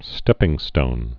(stĕpĭng-stōn)